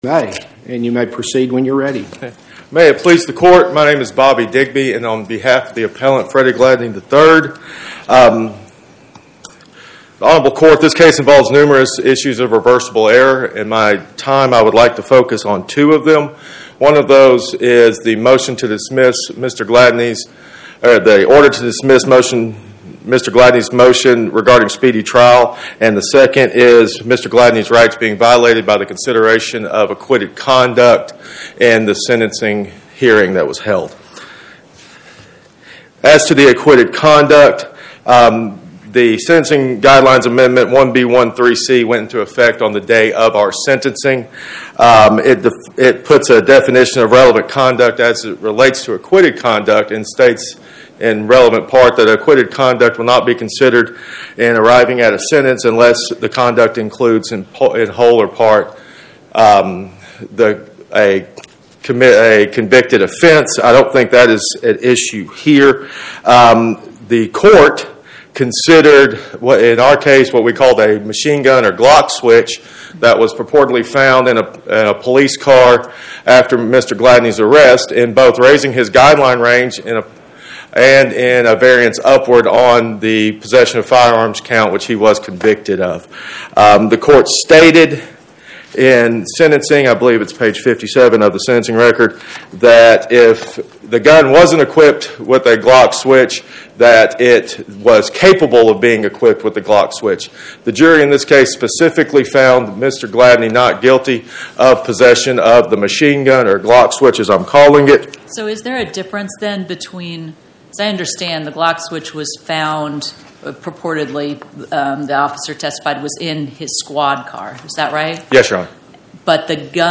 Oral argument argued before the Eighth Circuit U.S. Court of Appeals on or about 01/16/2026